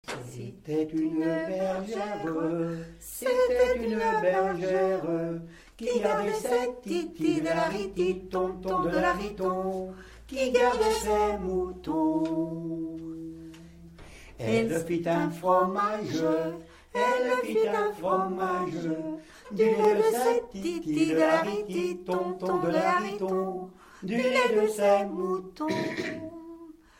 en duo avec sa femme
Genre laisse
Pièce musicale inédite